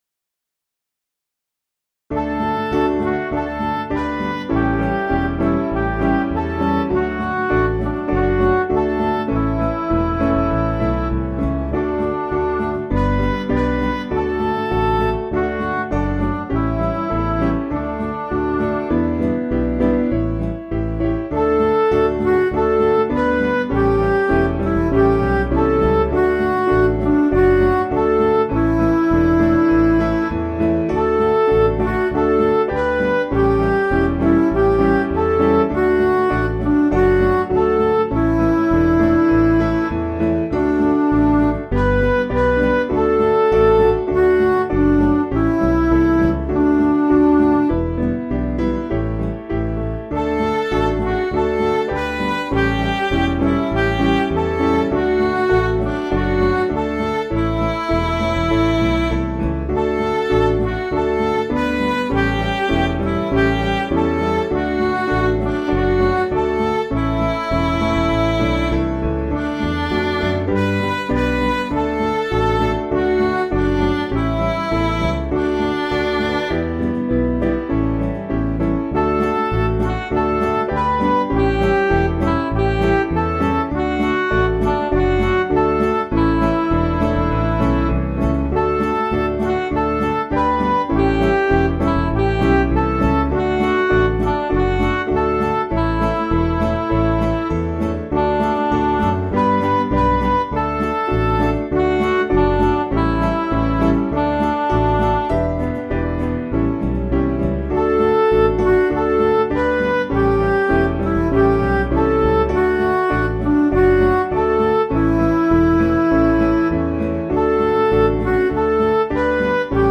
Danish Hymn
Piano & Instrumental